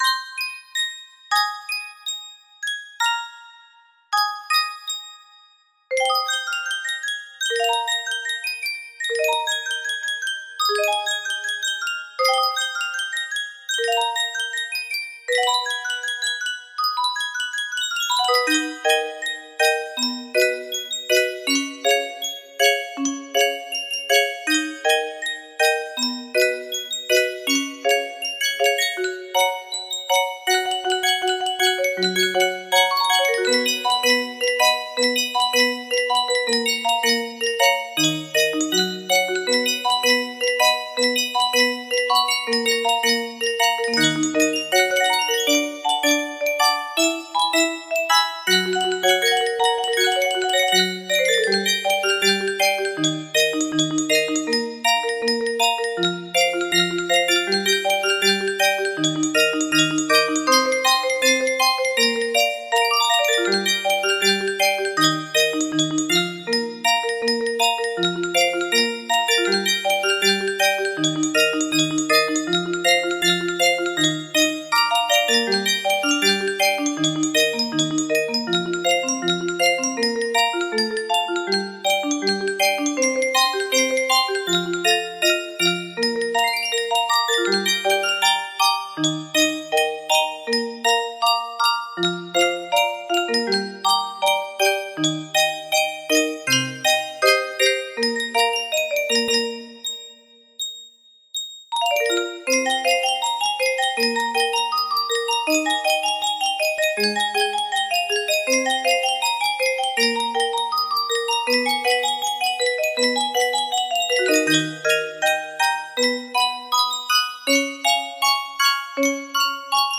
Full range 60
Anime song